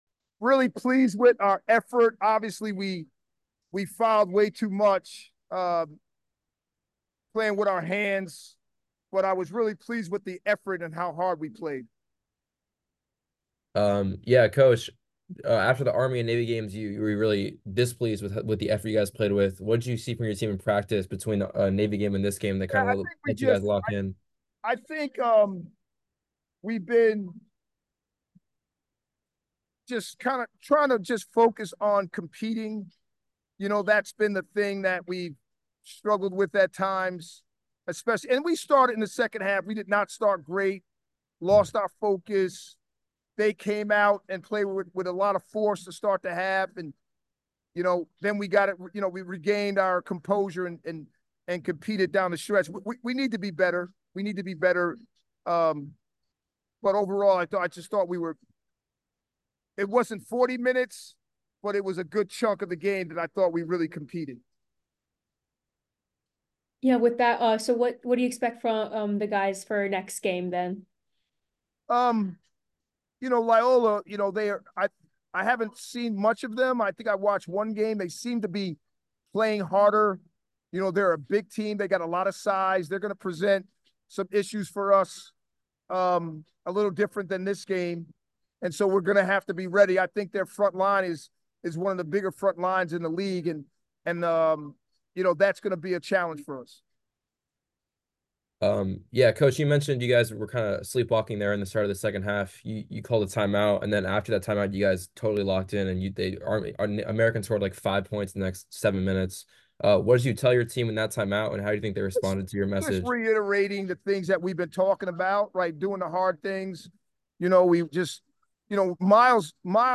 American Postgame Interview